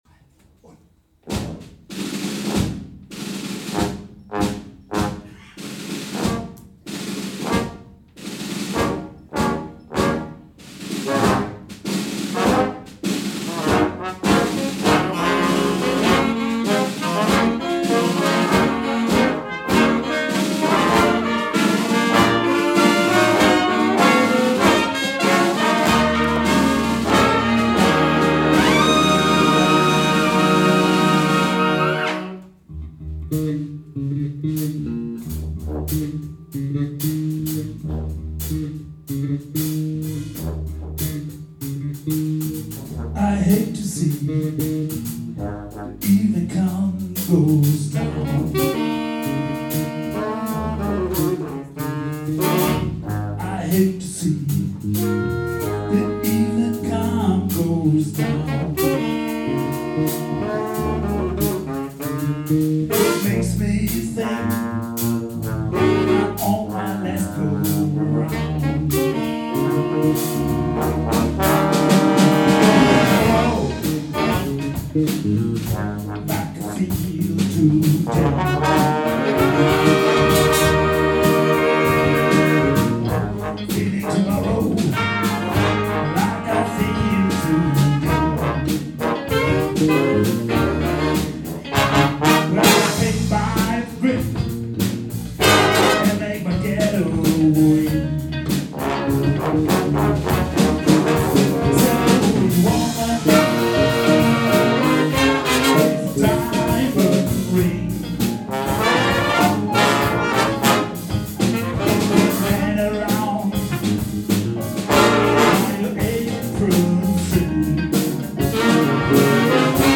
· Genre (Stil): Big Band
· Kanal-Modus: stereo · Kommentar